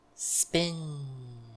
snd_spin.ogg